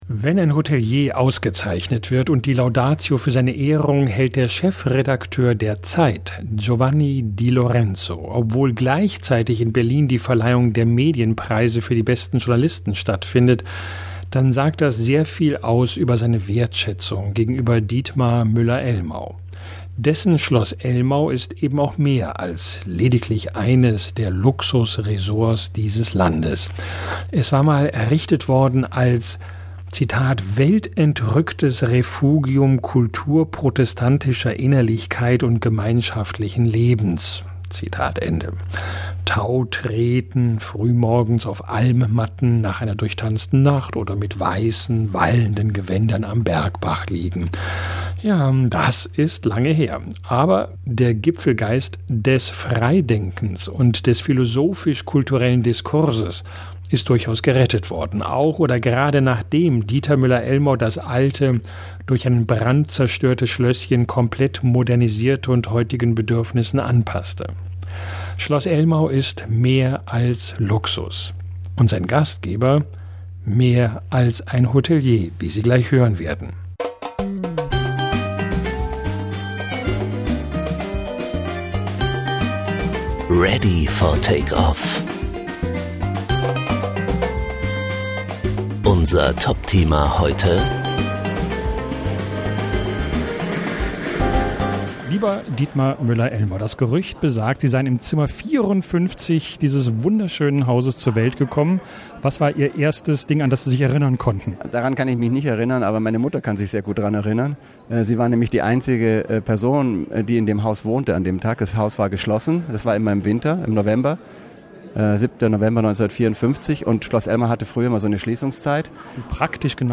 Freuen Sie sich auf eine hochsympathische, kluge Plauderei.